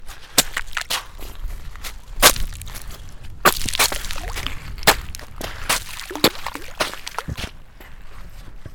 북+하모니카.mp3